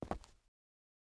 Concrete Walk - 0005 - Audio - Stone 05.ogg
Footsteps implemented